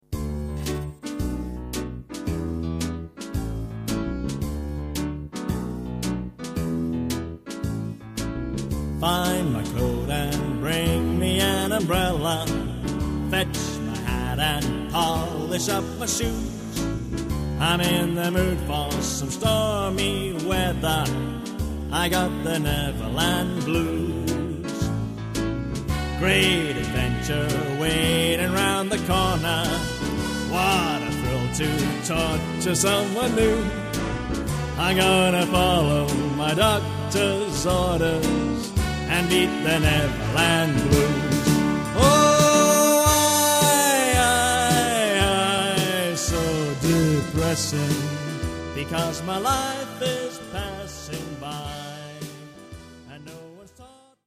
Sample from the Backing CD